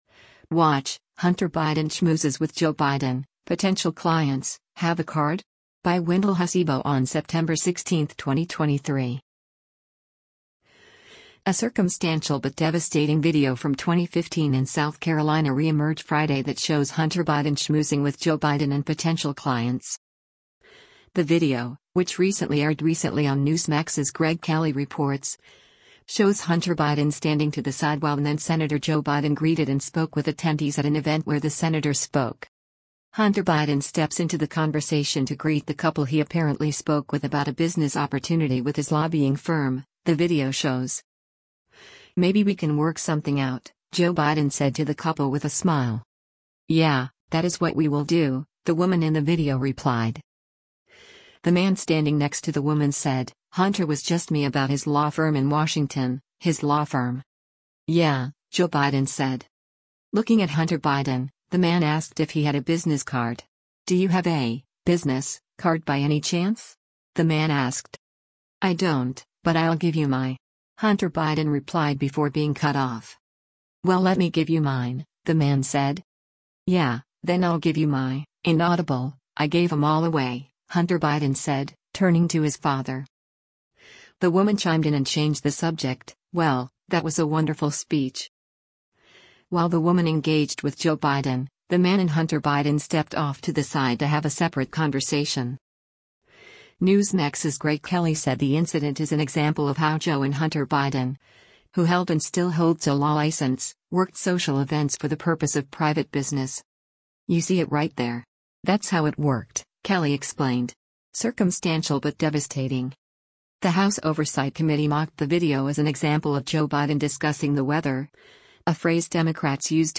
The video, which recently aired recently on Newsmax’s Greg Kelly Reports, shows Hunter Biden standing to the side while then-Sen. Joe Biden greeted and spoke with attendees at an event where the senator spoke.